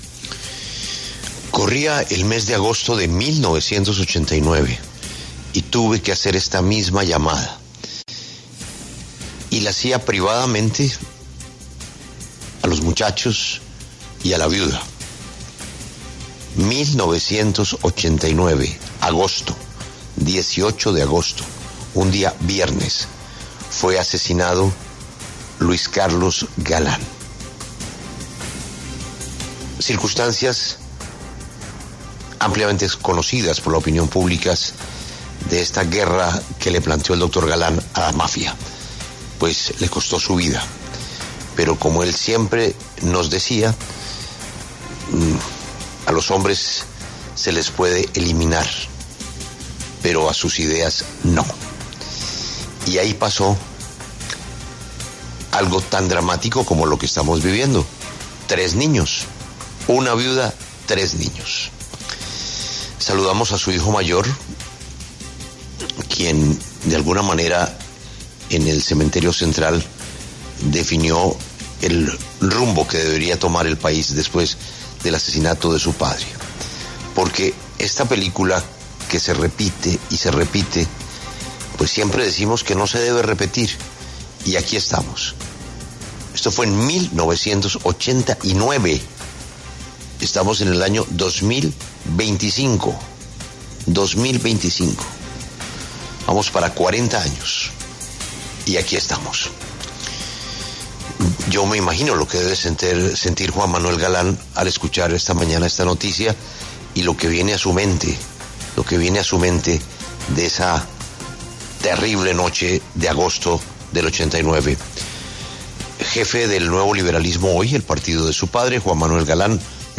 Juan Manuel Galán, hijo del también asesinado candidato presidencial Luis Carlos Galán, conversó con La W sobre la muerte de Miguel Uribe Turbay.